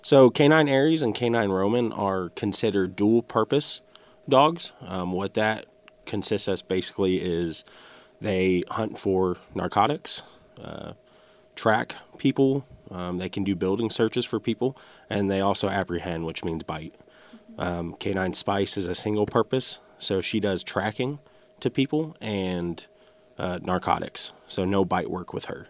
Howell County, MO. – Following the current project of Back the Blue K9 Edition, we had the Howell County Sheriff’s Department come in for an interview to describe the day in the life of a K9, specifically one that has only been on the job for a week.